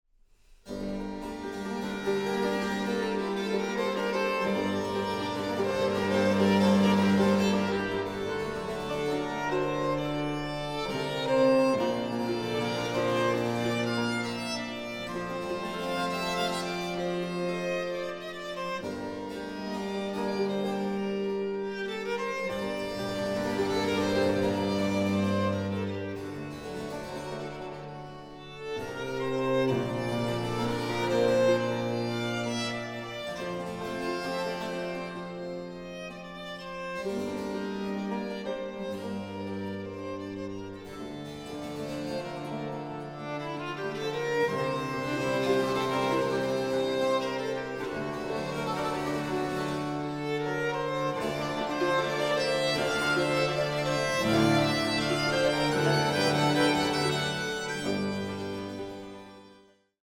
Baroque works on the theme of love in human and divine form
Soprano
The finely balanced ensemble and the agile, expressive voice